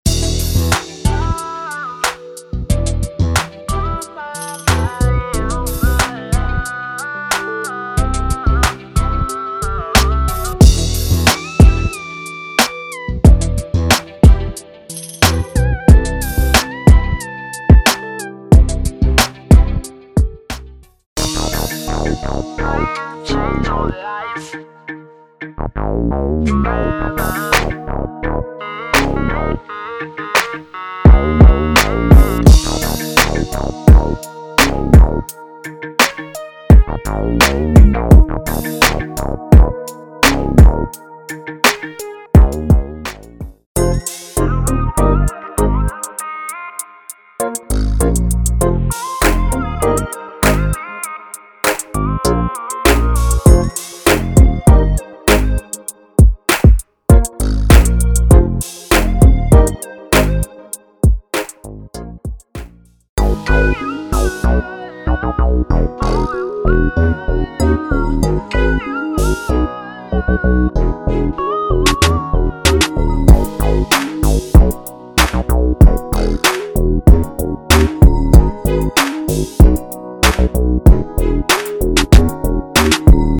为您带来来自西区的五个建筑套件。